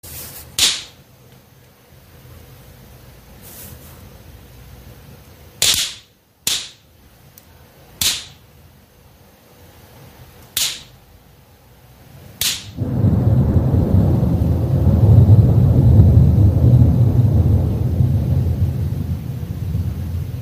5. 2024 CQ WPX cw Запис звуку електричних пробоїв на роз”ємах.